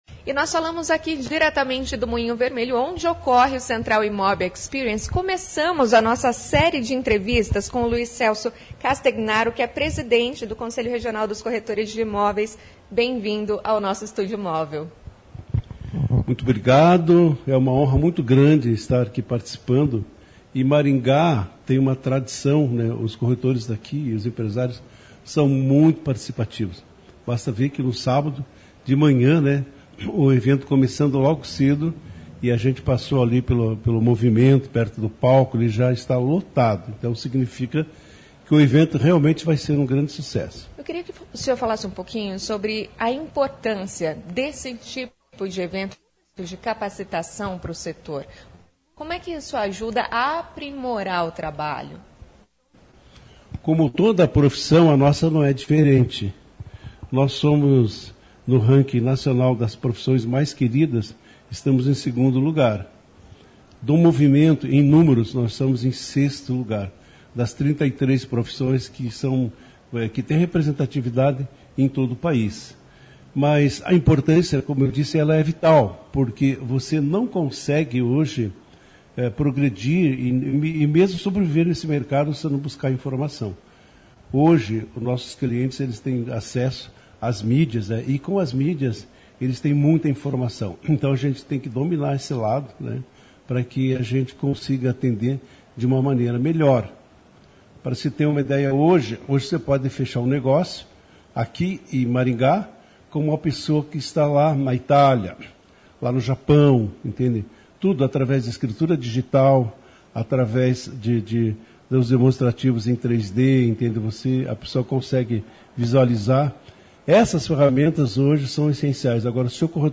Estúdio Móvel CBN